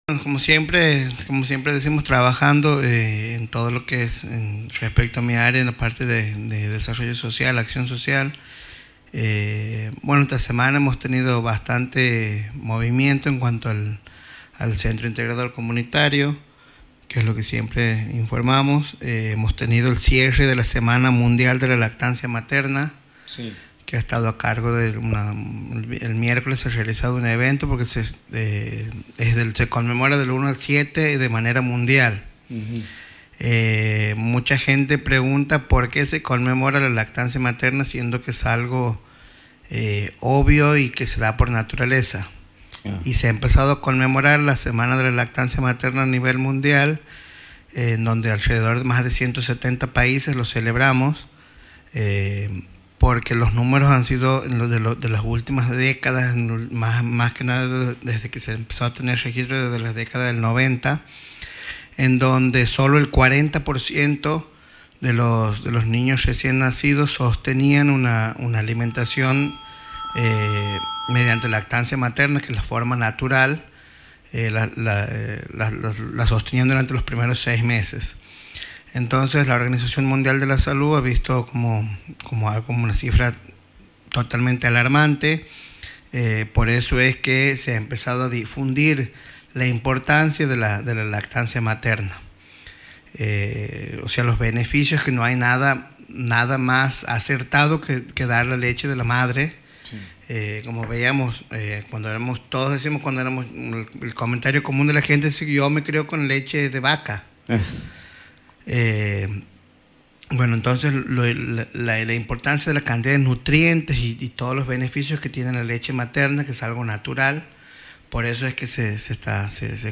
El Director de Acción Social de la Municipalidad de Fernández, Sr. Luciano Paz, brindó una entrevista radial este sábado donde dio a conocer las acciones en materia de salud que impulsa el municipio, y detalló los servicios de salud que presta el CIC del barrio Las Américas.